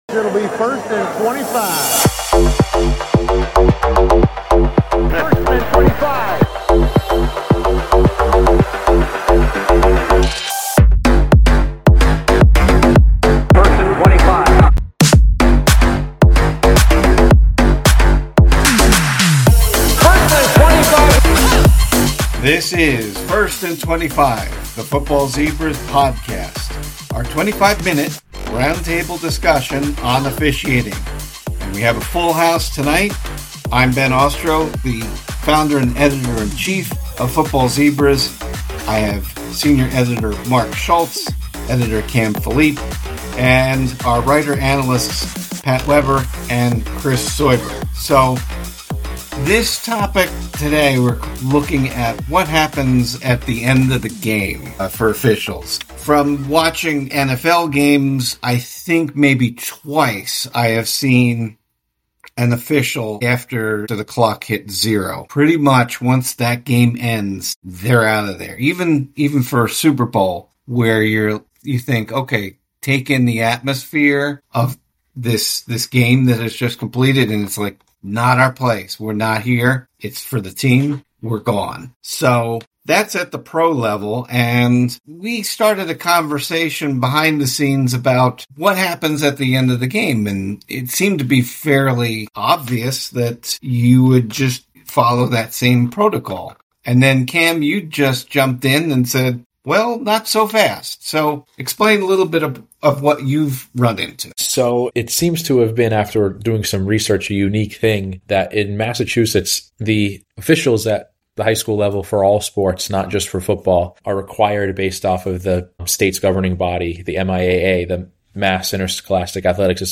We have a conversation about postgame procedures and officiating safety at the local level